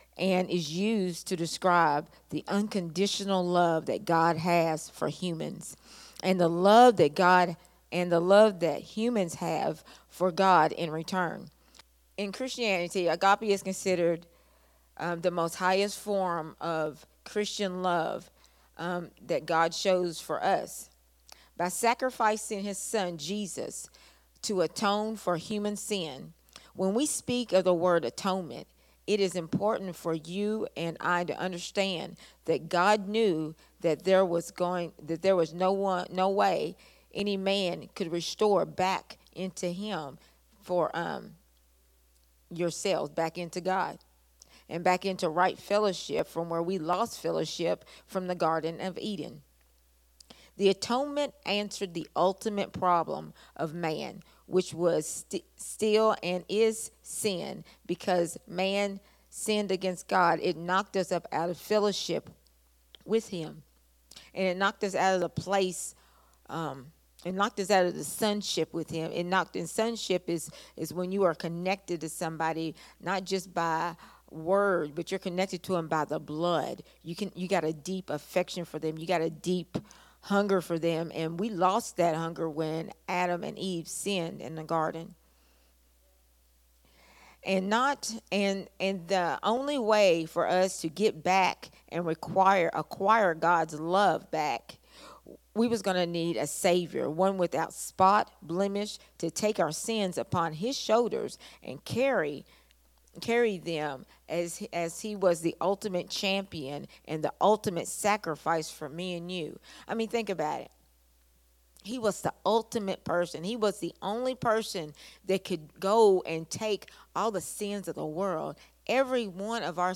a Sunday Morning Risen Life teaching
recorded at Unity Worship Center on Sunday, September 8th, 2024.